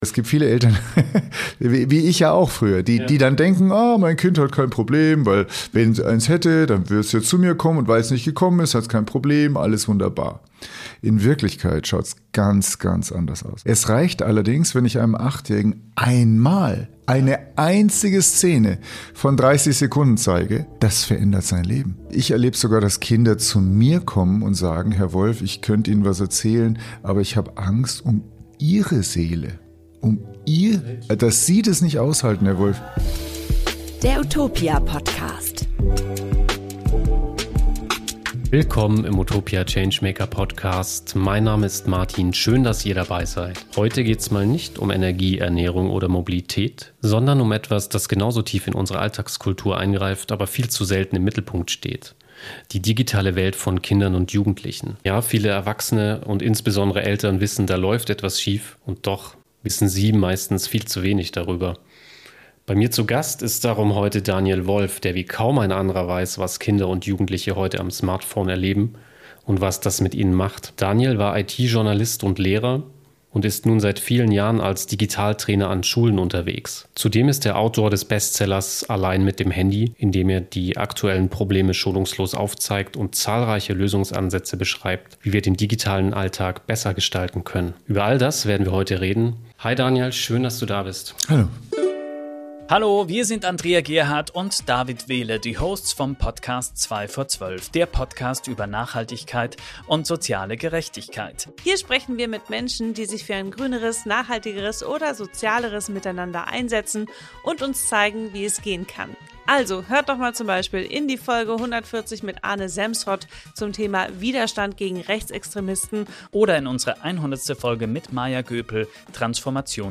Im Utopia Podcast sprechen unsere Redakteur:innen über Erkenntnisse aus ihren Recherchen und führen Interviews mit angesehenen Expert:innen. Energiewende, vegane Ernährung, nachhaltige Finanzen oder die Mobilität der Zukunft – mal alltagsnah, mal visionär.